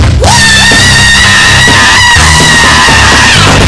The Scream (Very, VERY Loud)
scream.wav